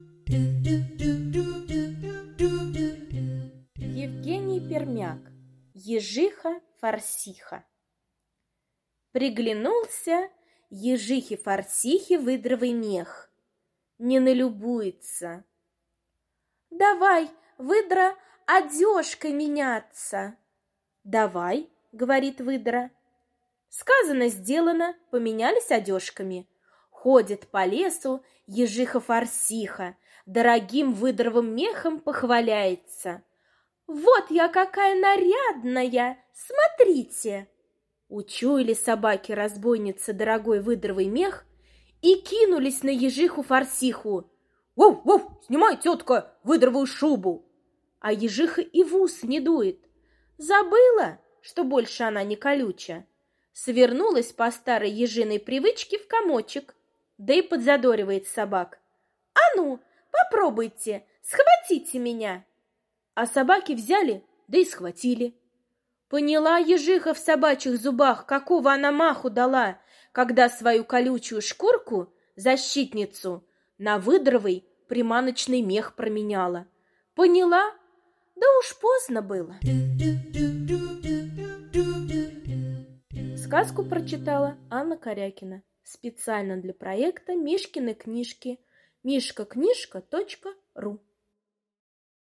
Ежиха-форсиха — аудиосказка Пермяка Е. Сказка про Ежиху, которая поменялась шубками с Выдрой из-за красивого меха...